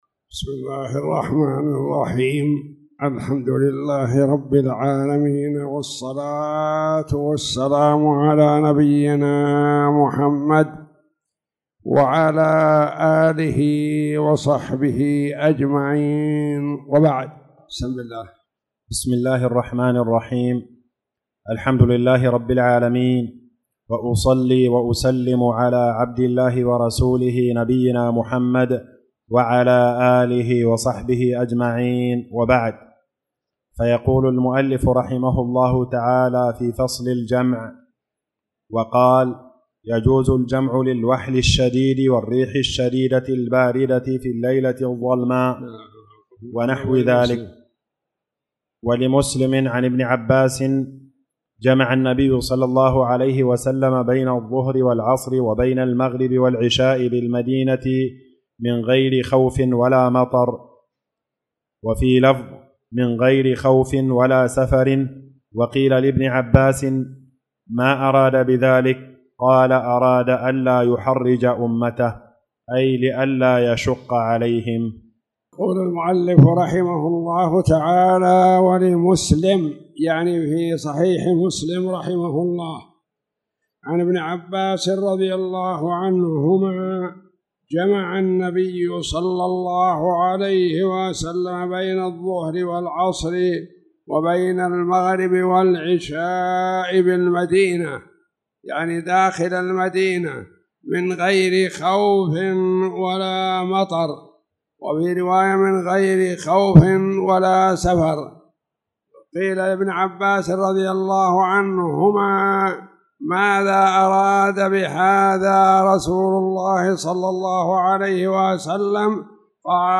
تاريخ النشر ٧ شعبان ١٤٣٧ هـ المكان: المسجد الحرام الشيخ